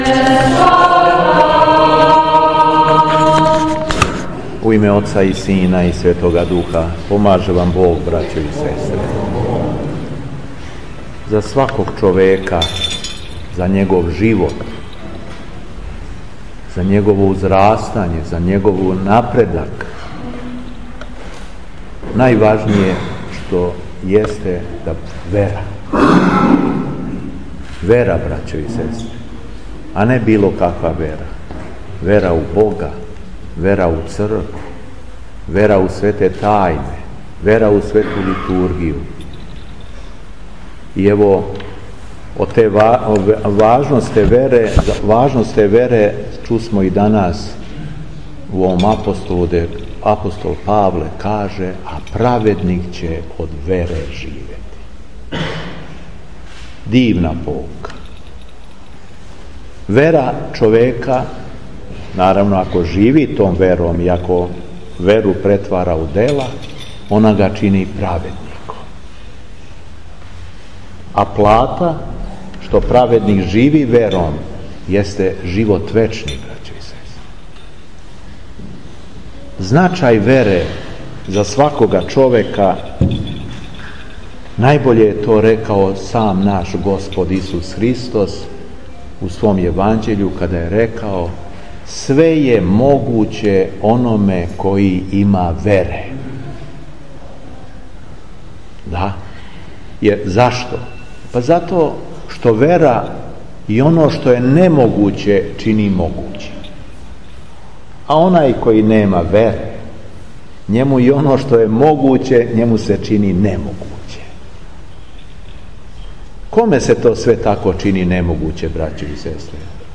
СВЕТА АРХИЈЕРЕЈСКА ЛИТУРГИЈА У РАЈКОВЦУ - Епархија Шумадијска
Беседа Епископа шумадијског Г. Јована